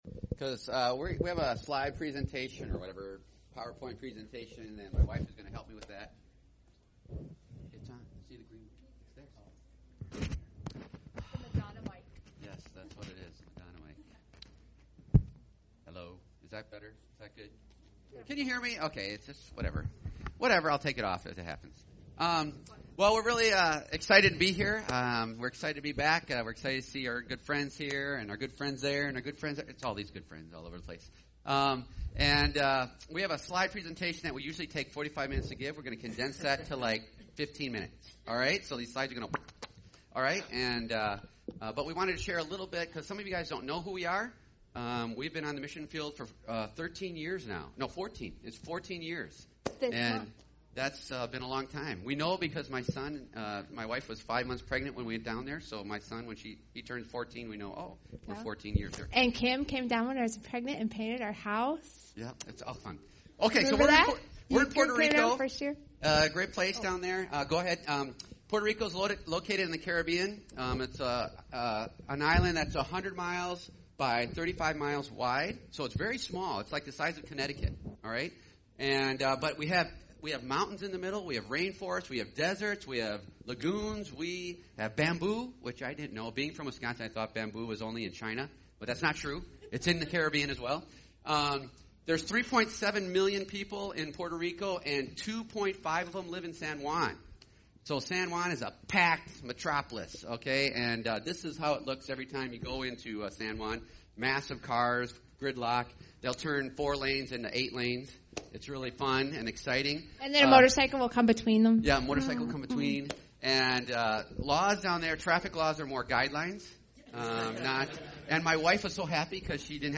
A sermon from our missionary friend from Campamente del Caribe in Puerto Rico